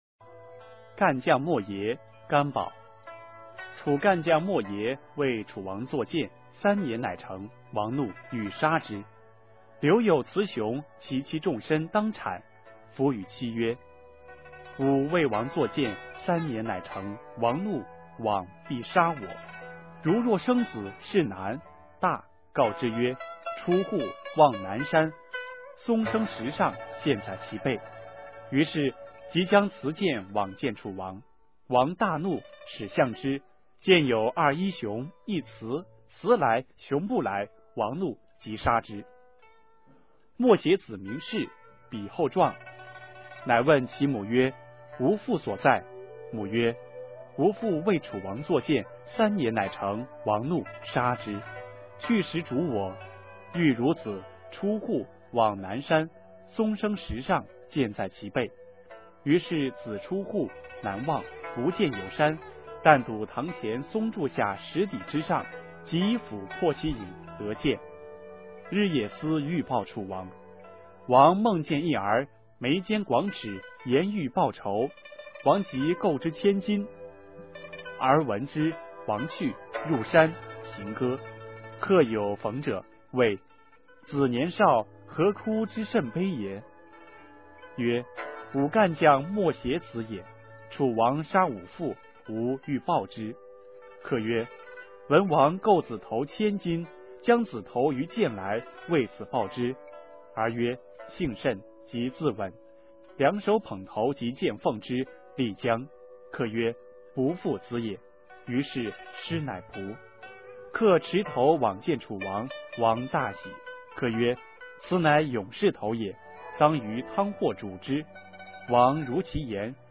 《干将莫邪》原文和译文（含朗读）　/ 佚名
语文教材文言诗文翻译与朗诵 初中语文八年级上册 目录